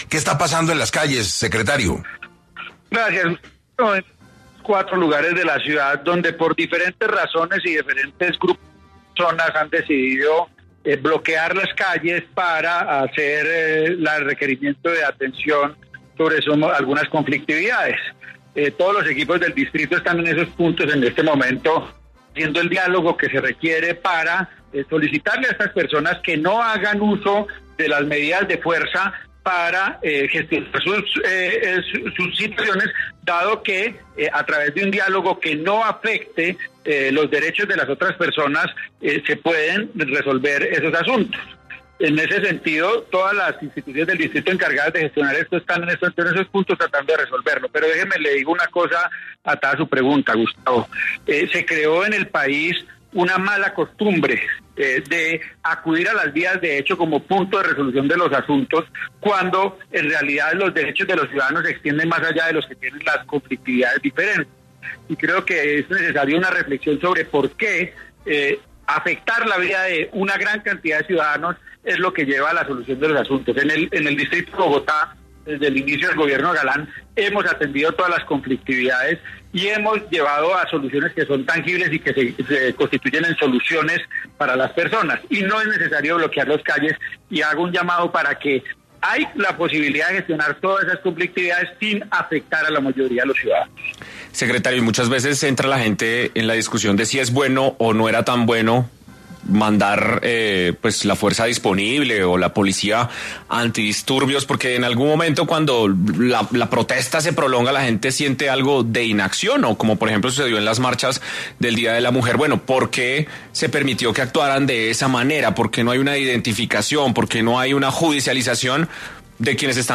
En una entrevista para 6AM, César Restrepo, Secretario de Seguridad de Bogotá, explicó los hechos y anunció cómo procederá la Alcaldía Mayor con el manejo de esta complicada situación.